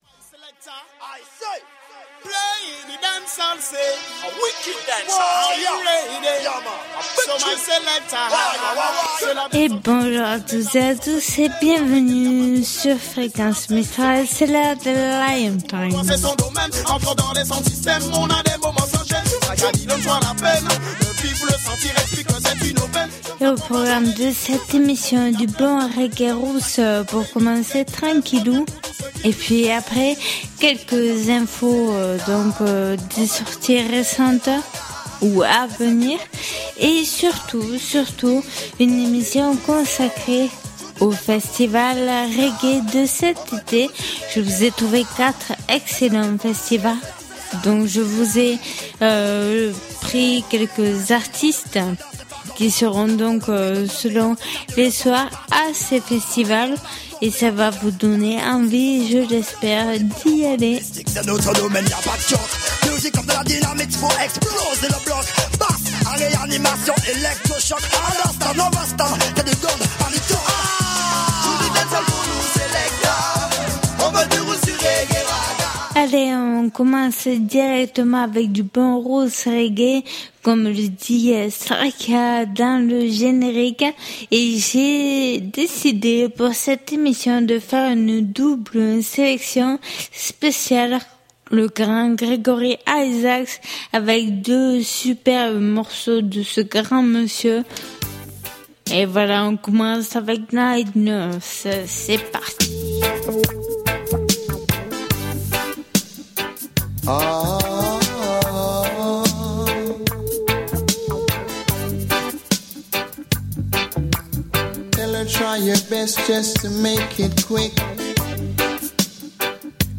Émission qui invite au voyage et à la découverte du roots du reggae et du dancehall. Que vous soyez novices ou grands connaisseurs de la musique reggae et la culture rasta, cette émission permet d’écouter de la bonne musique et surtout des artistes de qualité qui sont très rarement programmés sur d’autres radios.